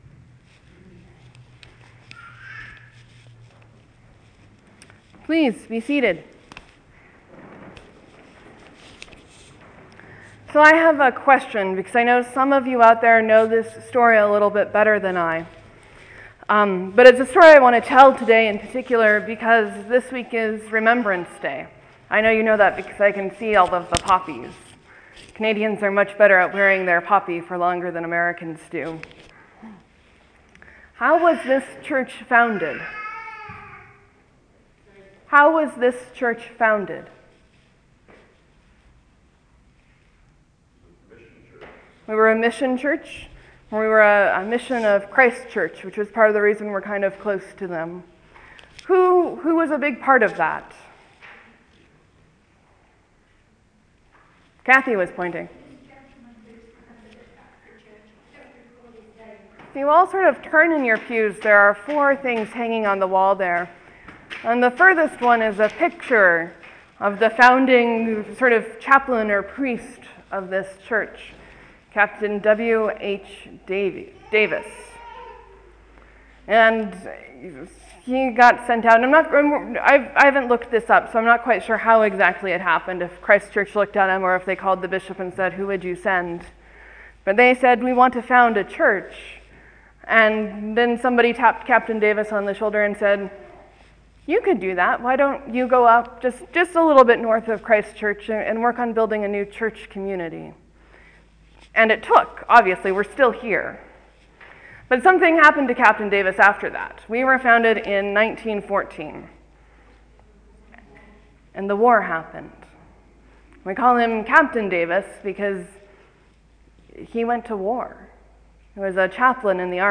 Sermon: The widow’s mite is about the widow and her mite, and what the people of God weren’t (and aren’t) hearing in Jesus’ comments.